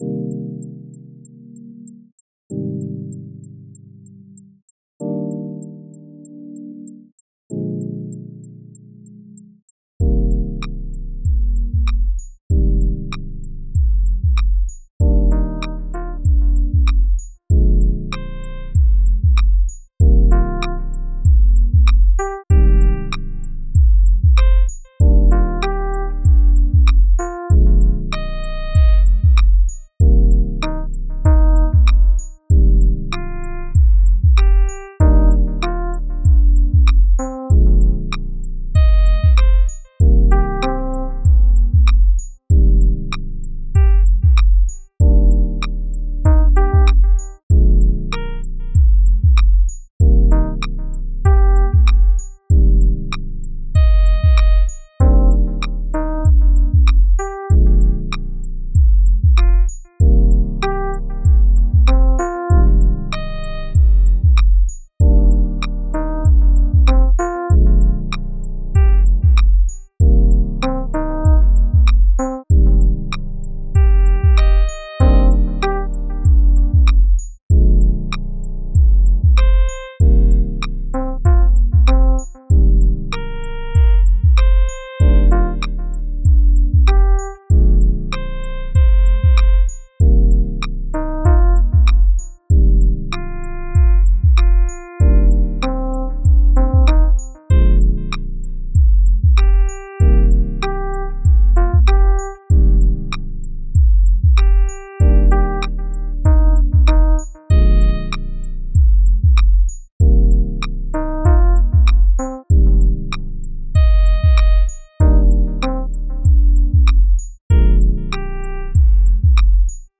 mac miller type beat를 claude code로 만들어보자
칸예 스타일을 더 담는것 같다.
제법 시티팝 느낌의 곡이 나왔지만 이것도 20초짜리 루프.
하지만 없어지지 않는 찢어지는 느낌...